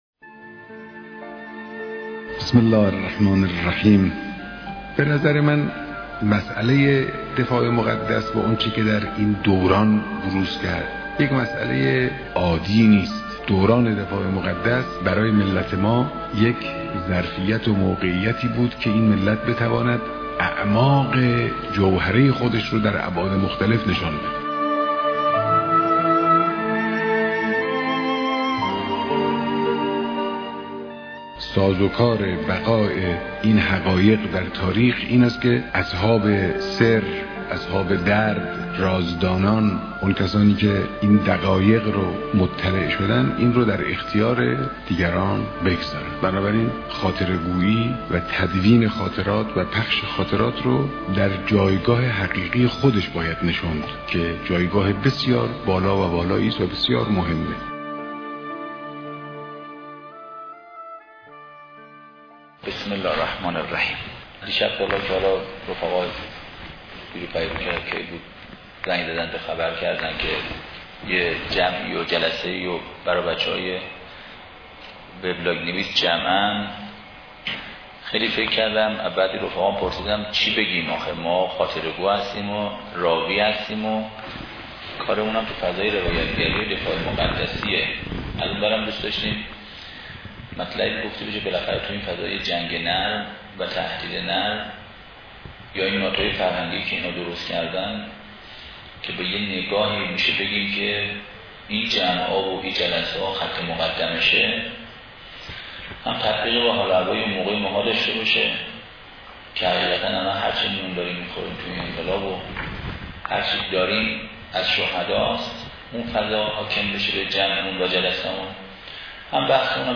ravayatgari78.mp3